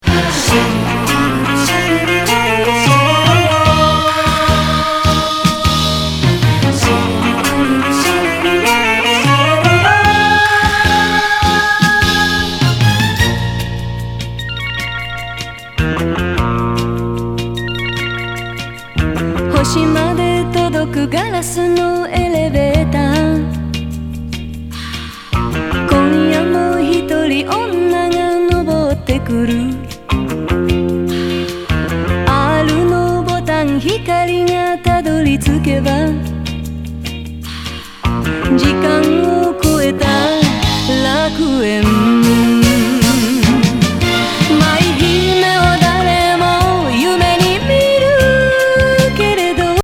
ファンキー・ムード歌謡！